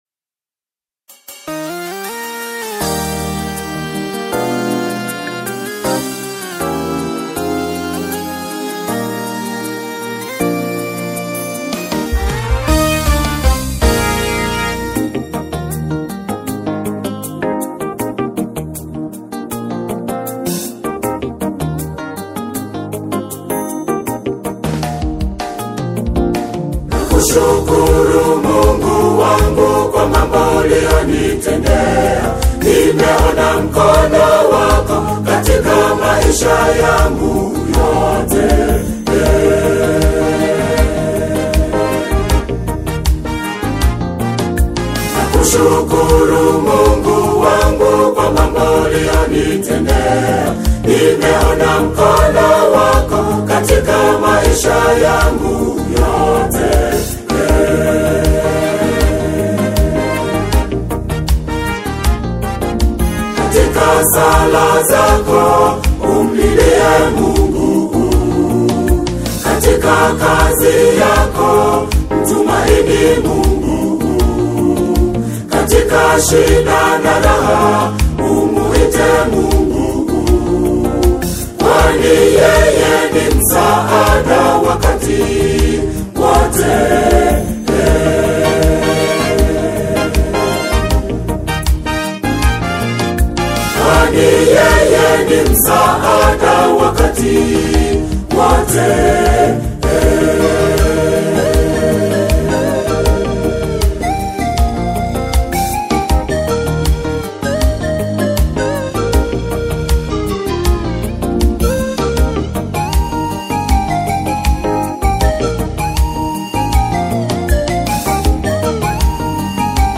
an evocative and heartfelt single